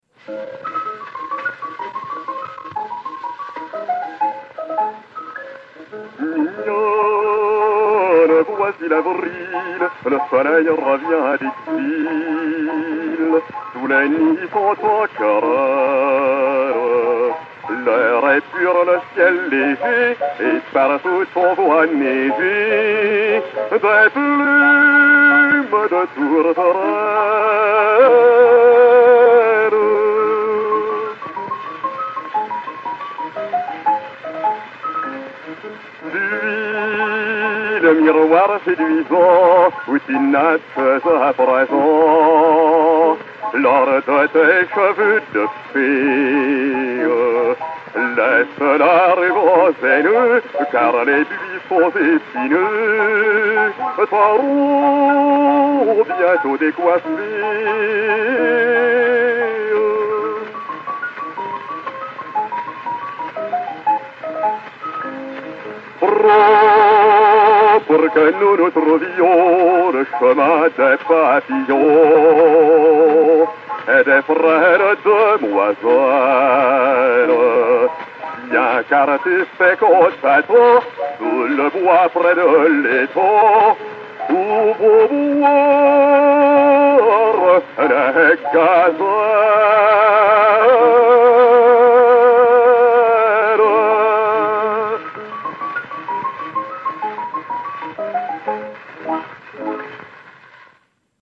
basse, avec piano
Odéon 6158, enr. à Paris en 1904/1905